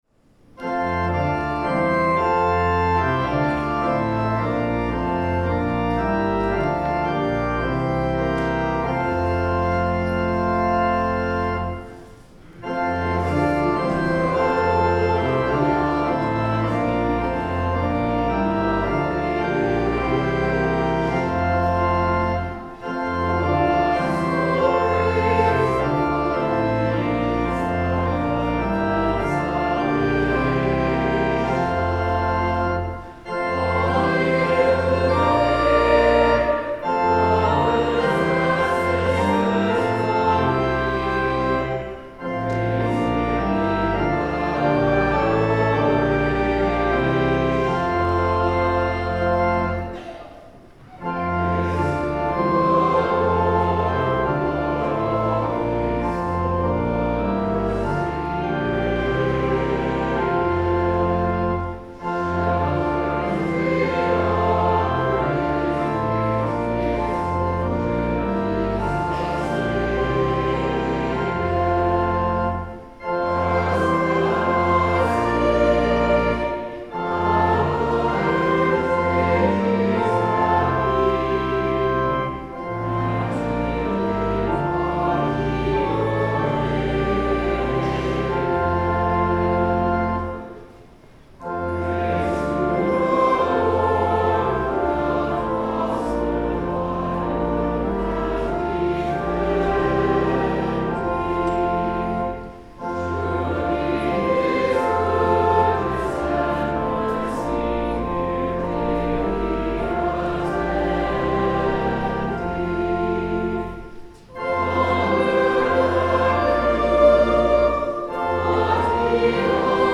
Sermon
Prayers of the People The Lord’s Prayer (sung)